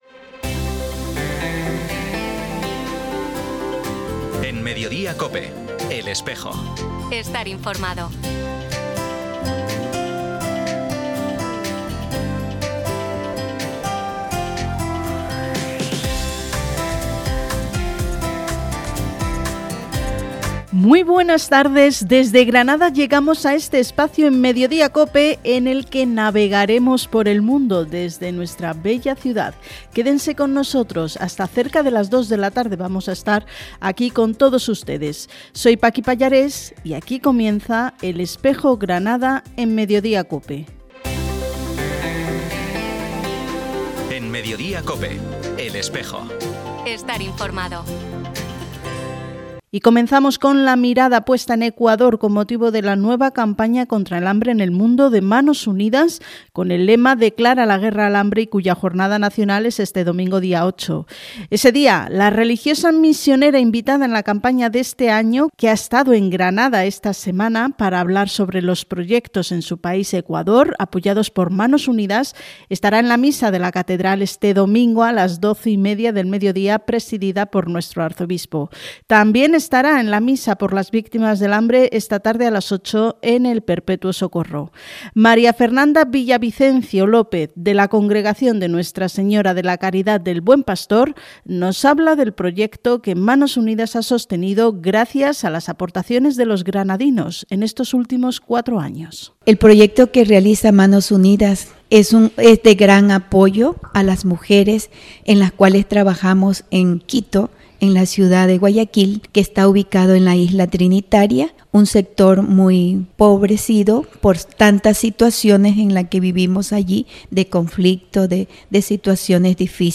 Programa emitido en COPE Granada y COPE Motril, el 6 de febrero de 2026.